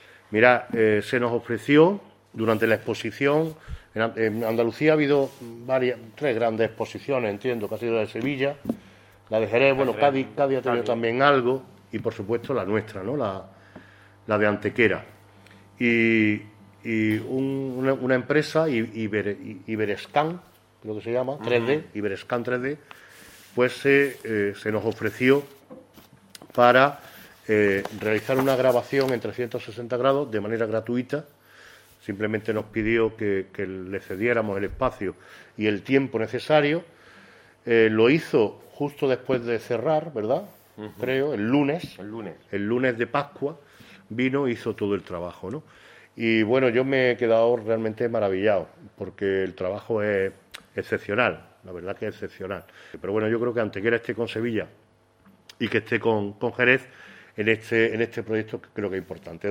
El alcalde Manolo Barón ha destacado, durante la presentación en el MVCA de esta iniciativa –en la que han estado presentes también representantes de las Cofradías de Pasión de la ciudad–, la importancia del desarrollo de este tipo de actividades complementarias que, además de atestiguar lo que dio de sí esta magna exposición, posibilitan la difusión del rico y destacado patrimonio histórico, artístico y cofrade local con el beneficio que tanto para Antequera como para sus cofradías representa.
Cortes de voz